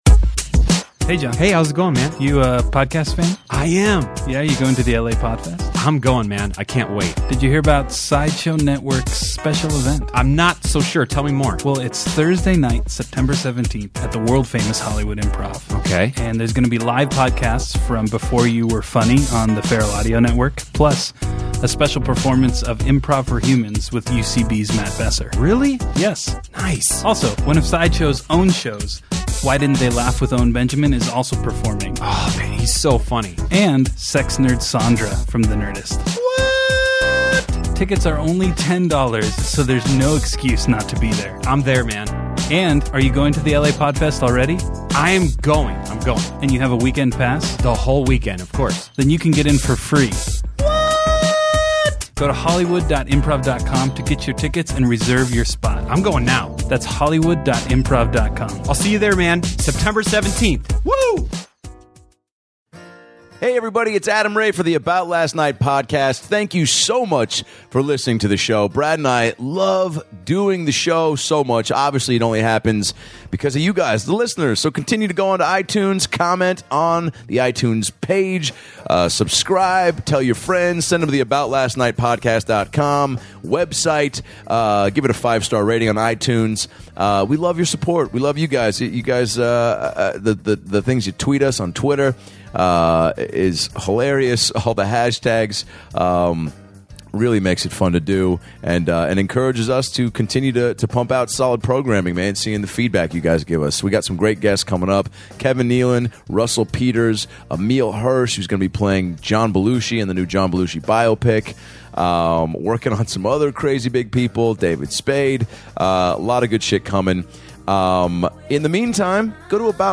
WORKAHOLICS star Anders "DERS" Holm, stops by to talk about going to college in Wisconsin, getting his start in LA, and how having a baby has changed his life. It's a great interview from top to bottom.